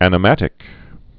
(ănə-mătĭk)